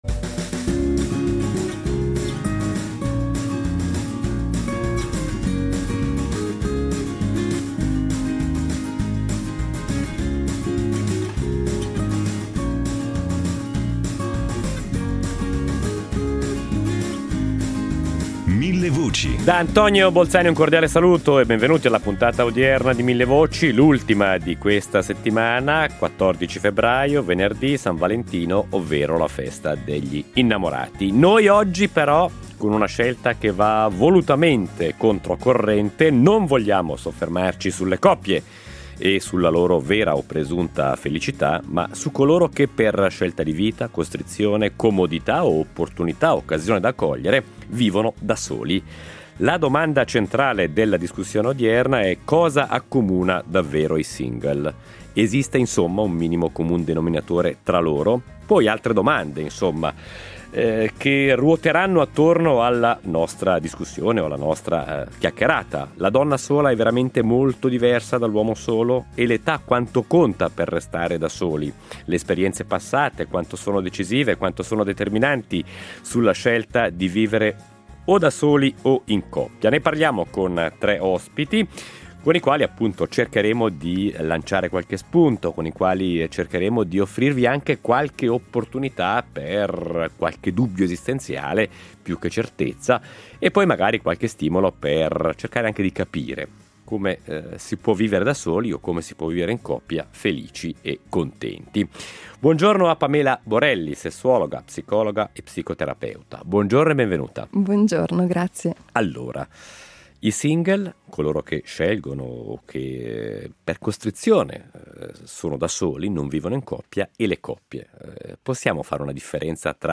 Ne parliamo, ascoltando anche i vostri punti di vista, con gli ospiti.